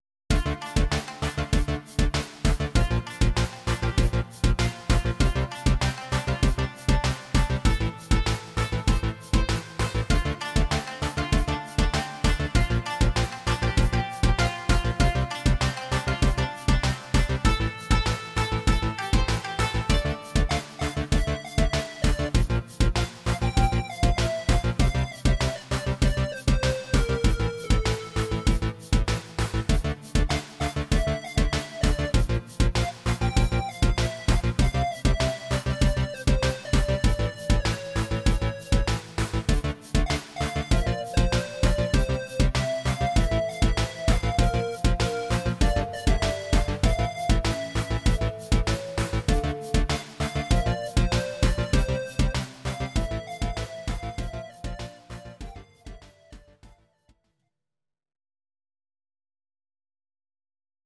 Audio Recordings based on Midi-files
Pop, Rock, 1980s